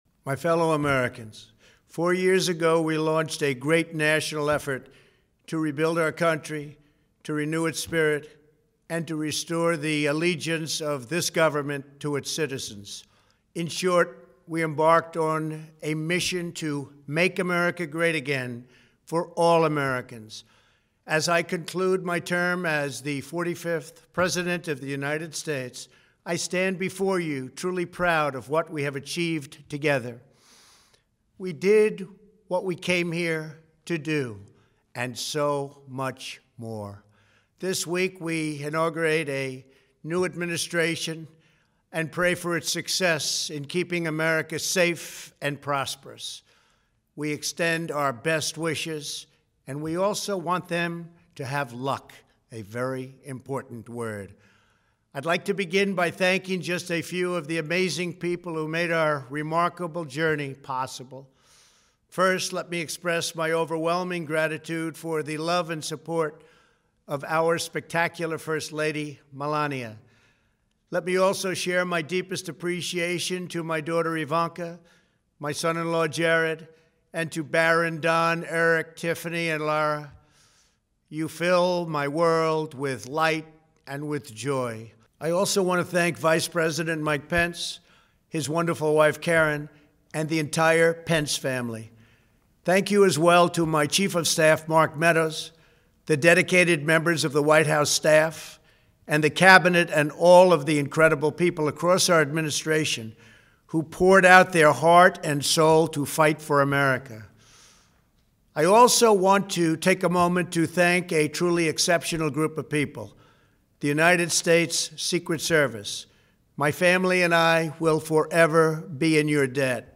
January 19, 2021: Farewell Address | Miller Center
Presidential Speeches
trump_farewell_address.mp3